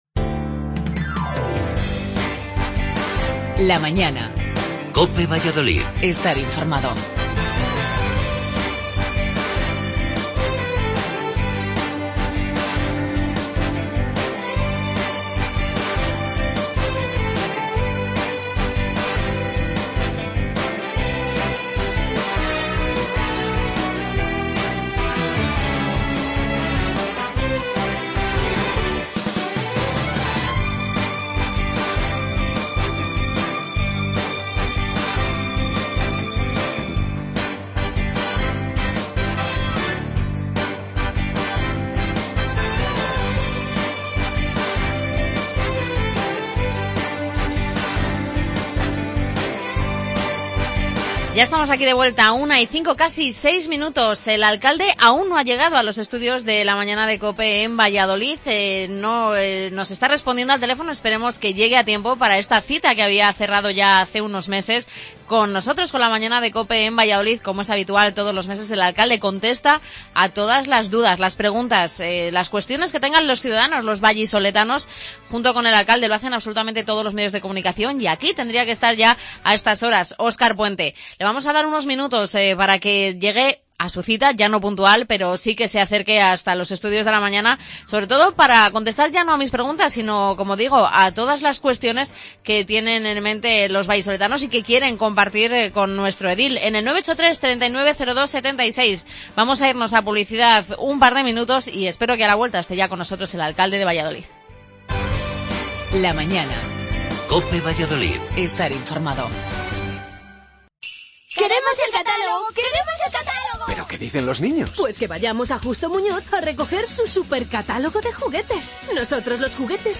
Óscar Puente, alcalde de Valladolid, responde a las preguntas de nuestros oyentes.